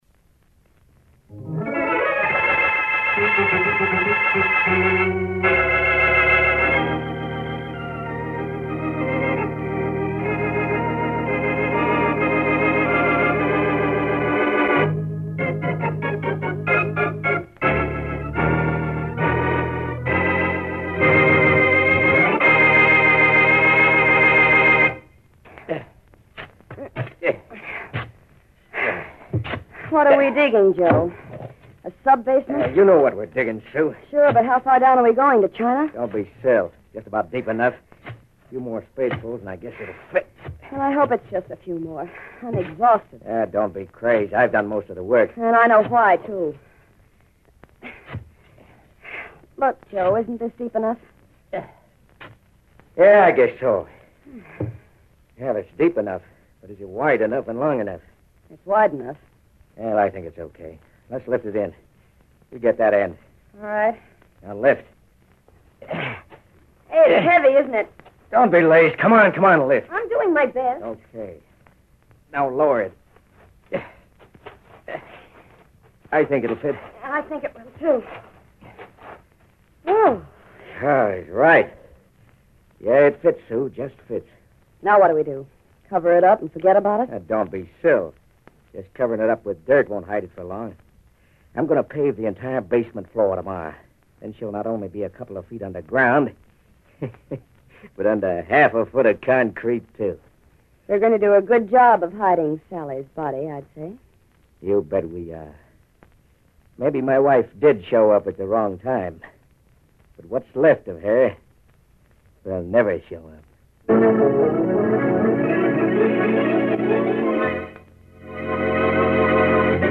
Boston Blackie, Starring Richard Kollmar and Jan Miner